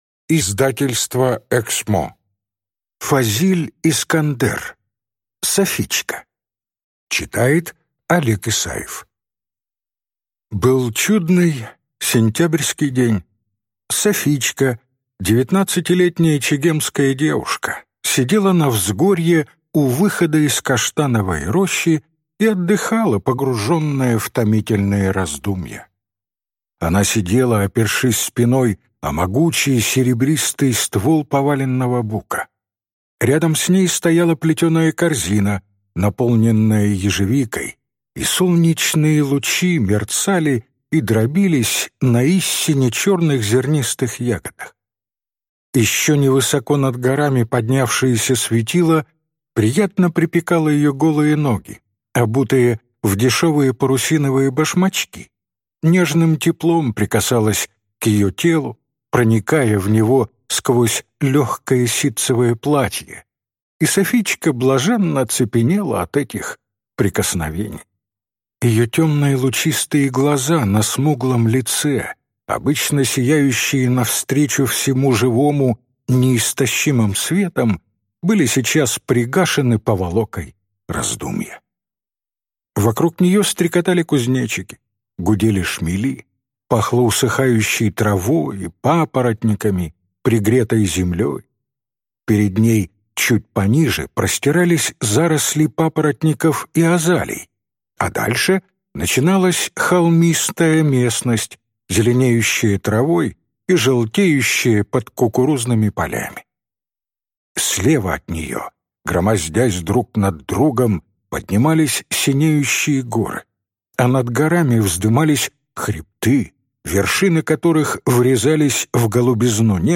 Аудиокнига Софичка | Библиотека аудиокниг